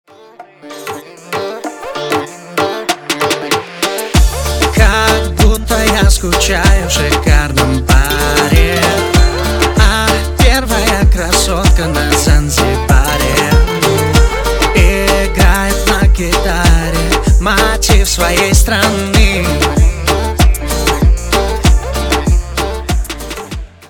поп
ритмичные
восточные мотивы
dance
красивая мелодия
инструментальные